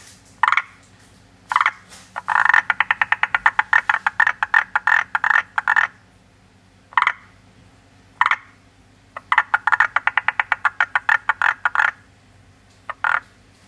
モリアオガエルの鳴き声